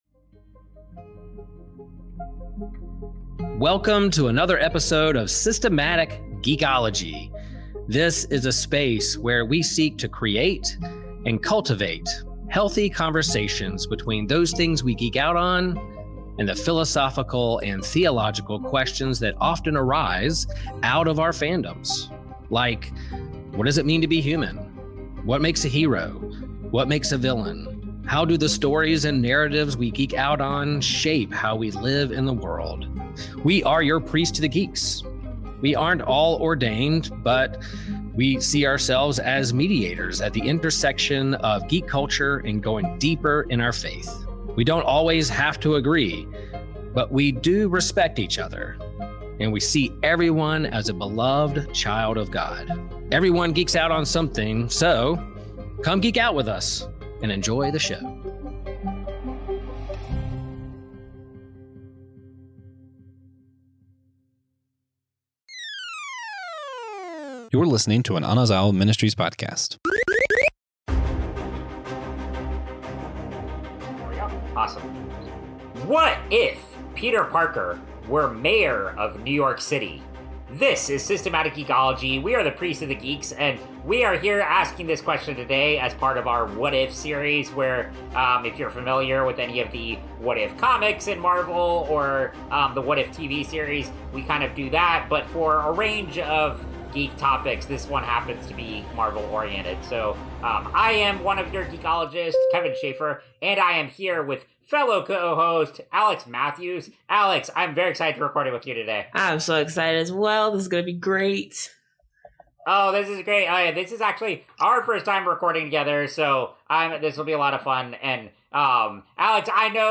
With witty banter and clever insights, they navigate through nostalgia, culture, and the quirks of holiday traditions, all while keeping it light-hearted and fun.